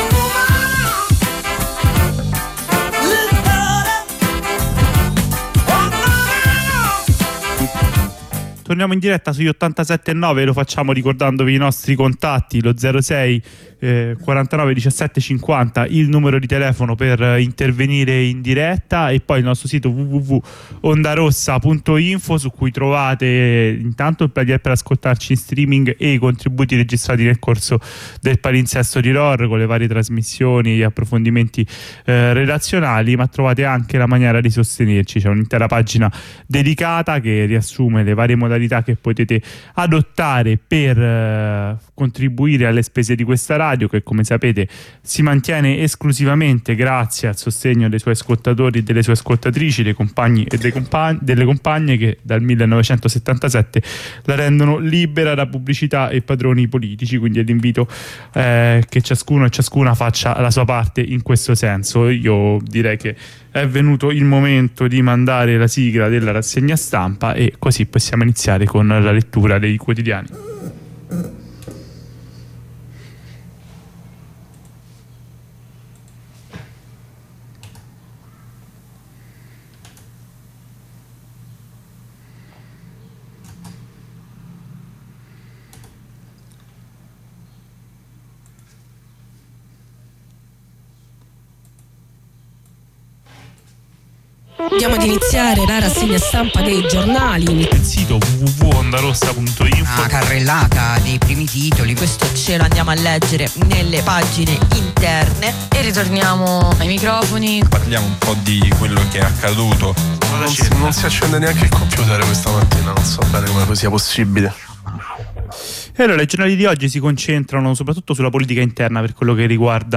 I giornali di oggi letti su Ondarossa